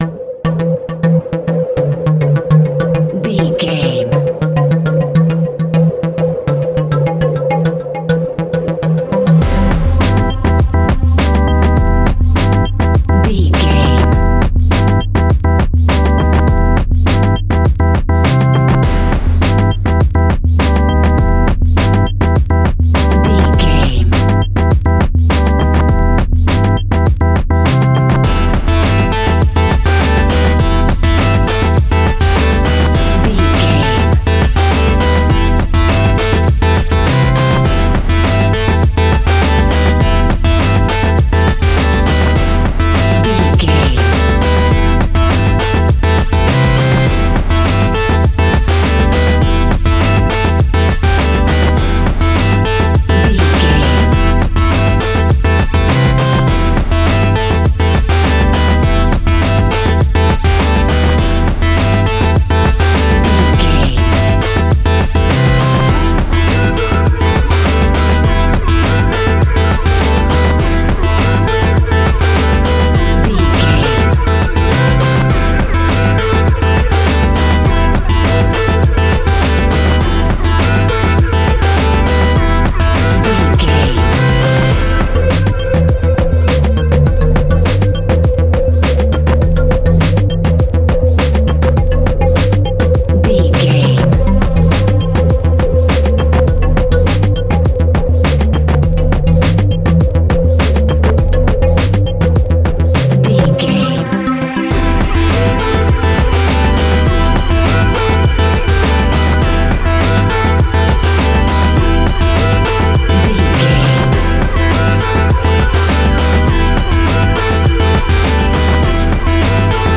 In-crescendo
Aeolian/Minor
hip hop
hip hop instrumentals
downtempo
synth lead
synth bass
synth drums
turntables